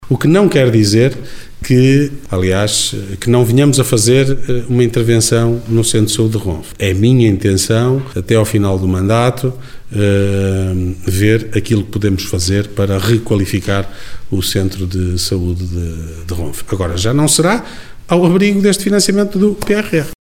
Declarações de Ricardo Araújo, presidente da Câmara Municipal de Guimarães. Falava esta semana aos jornalistas, no final da reunião do executivo municipal.